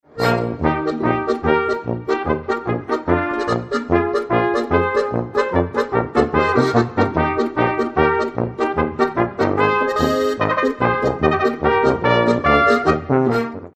Musik aus dem Mostviertel